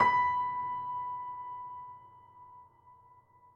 Steinway_Grand
b4.mp3